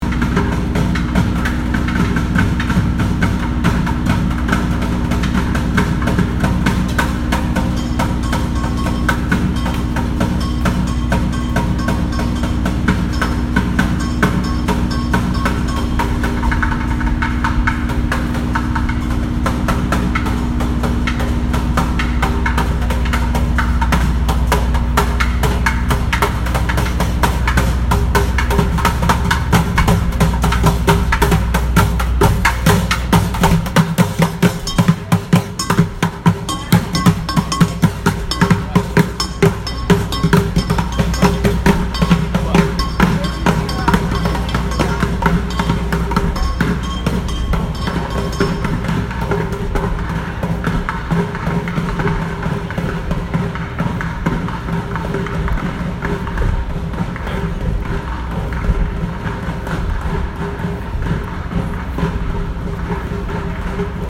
Boston street drummers
In each of the cities the band performed he would make field recordings of the spaces and streets they visited, whilst on each flight composing musical pieces on his phone.